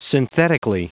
Prononciation du mot synthetically en anglais (fichier audio)
Prononciation du mot : synthetically